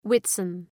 Προφορά
{‘wıtsən}